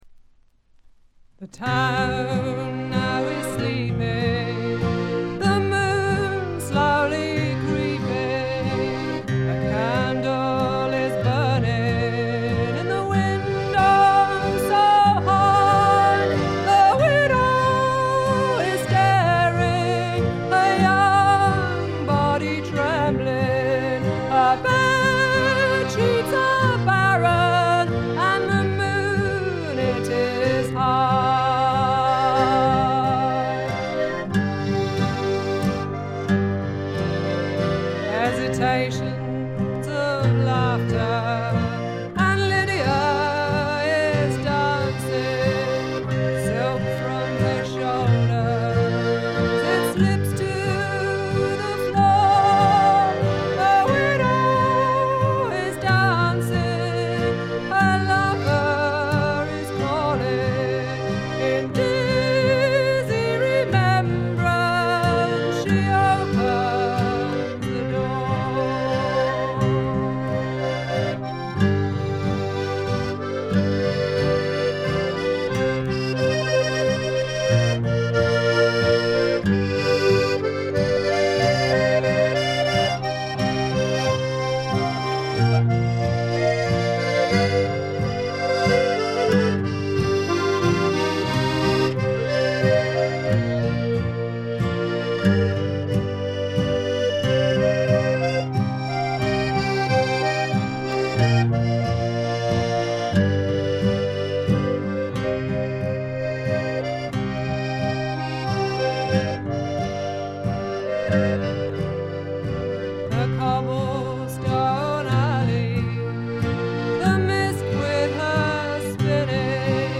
ところどころでチリプチ。
英国の女性フォークシンガー／ギタリスト。
味のあるアルとト・ヴォイスで淡々と歌っていクールなものです。
試聴曲は現品からの取り込み音源です。
Vocals, Guitar, Electric Bass, Banjo
Button Accordion
Piano